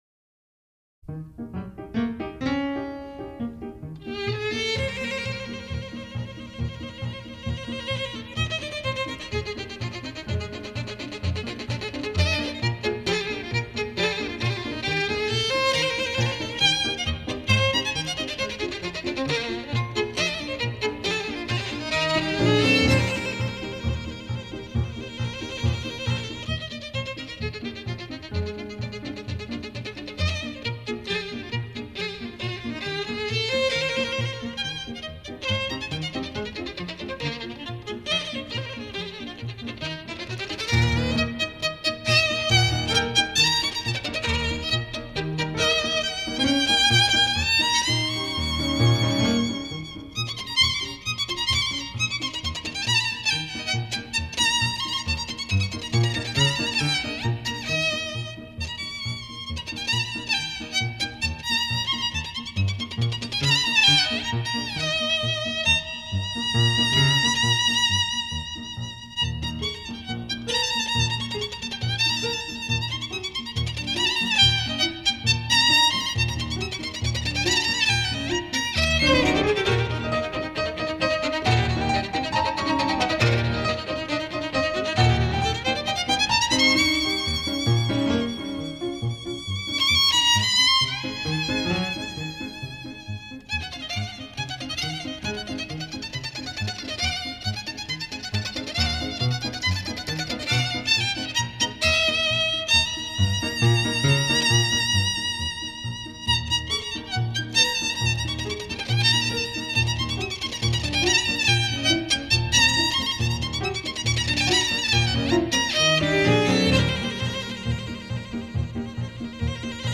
流 派：古典